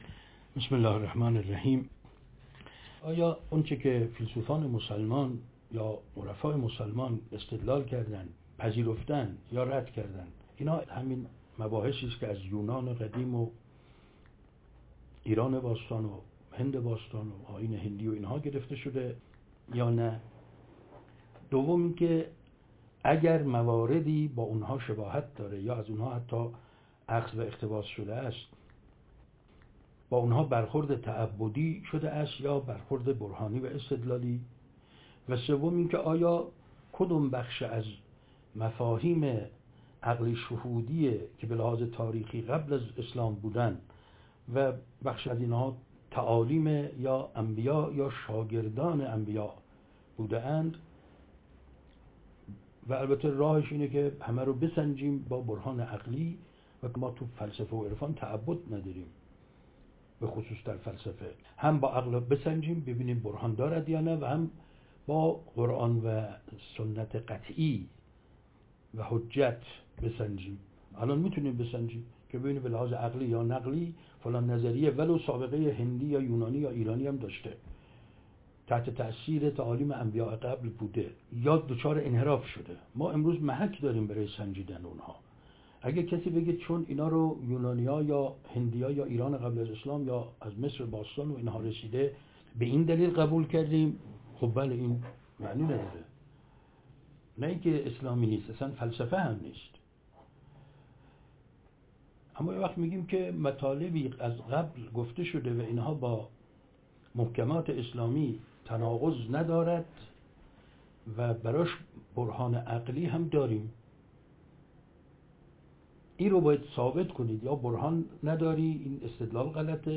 بزرگداشت فیلسوف نوآور، جناب ملاصدرا _ ۱۴۰۳
شبکه چهار - 11 خرداد 1403